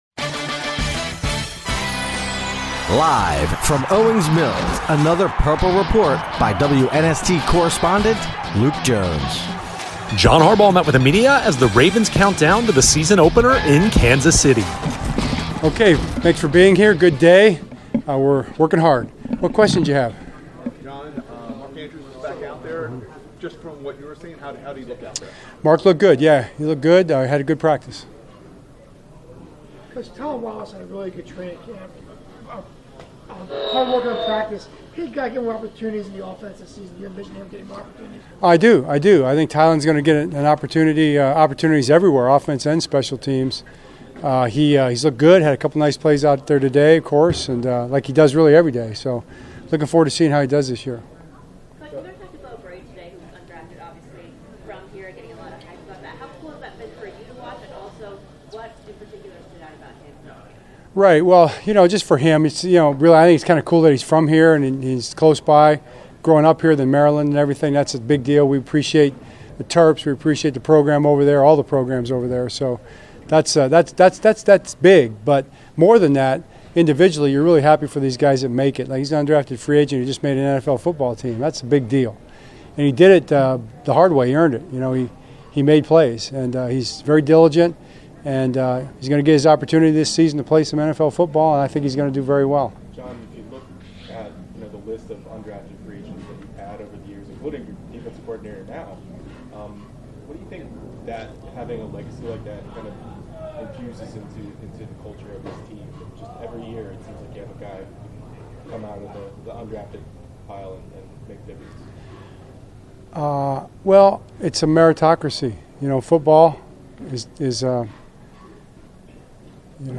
Locker Room Sound
John Harbaugh speaks with media following Friday's Ravens practice in Owings Mills